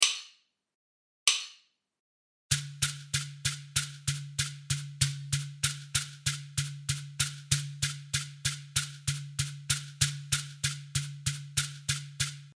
Fond rythmique
un rythme du débit en double-croches est utilisable pour votre entraînement
debit-à-la-double-croche-binaire-96-bpm.mp3